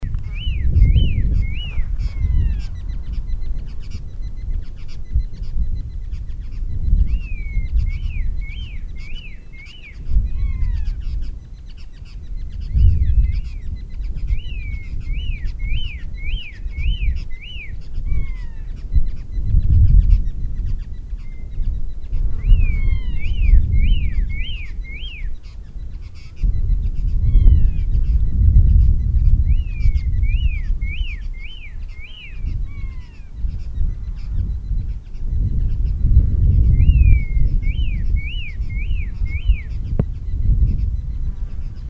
Babax lancéolé ( Babax lanceolatus ) ssp latouchei
Chant et cris enregistré le 09 mai 2012, en Chine, province du Fujian, réserve de Dai Yun Shan.